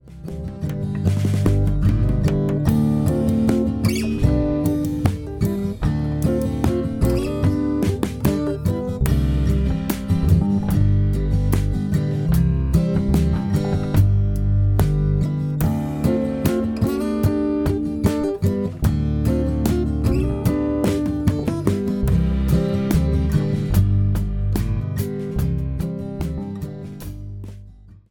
groove based guitar, drums and bass in a medium swung tempo, with an indie folk kind of vibe.